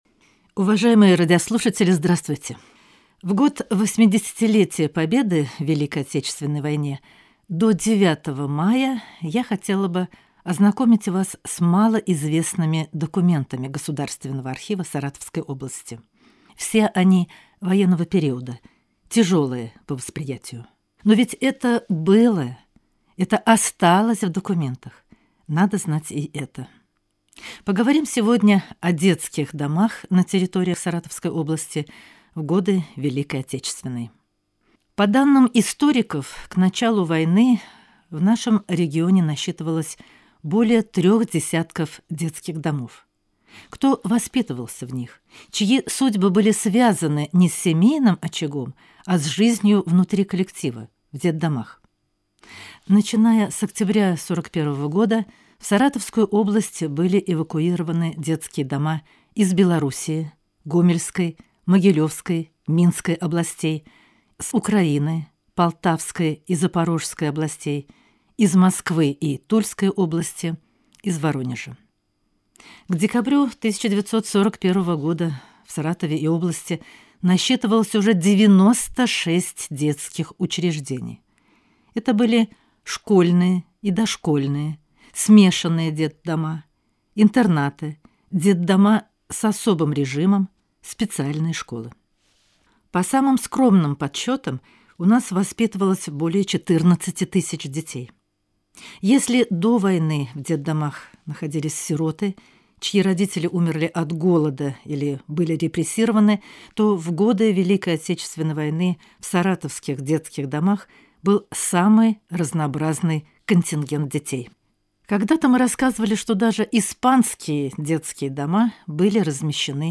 В рамках утренней информационной программы ГТРК Саратов прозвучал сюжет, посвящённый одной из наиболее драматичных страниц Великой Отечественной войны – эвакуации и спасению детей, оказавшихся под угрозой фашистской оккупации. Саратовская область приняла первых эвакуированных детей из Белоруссии и Украины уже летом 1941 года.